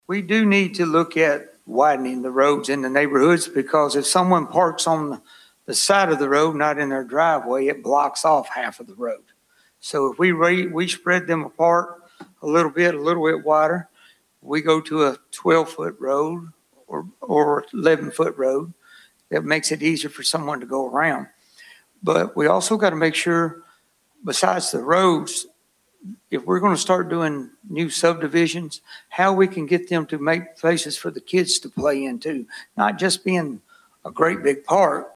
The League of Women Voters hosted the candidates Monday night for its final forum of the night.